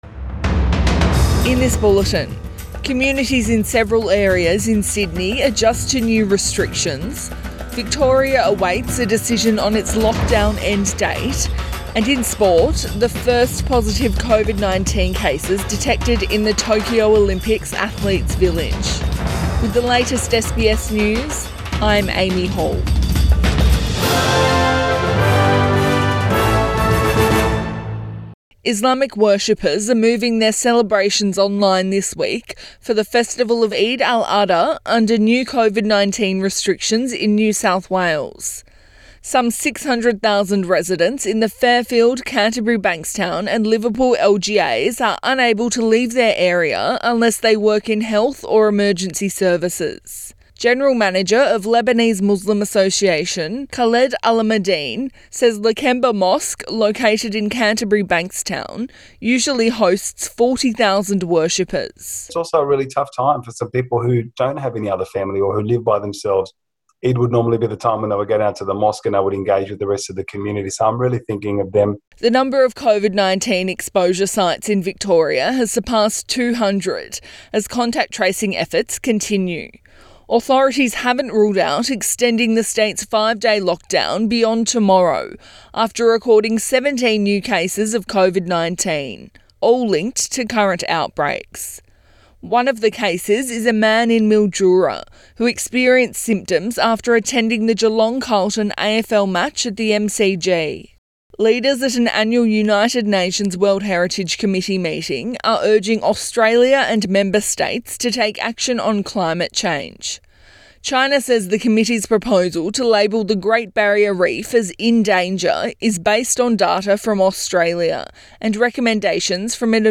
AM bulletin 19 July 2021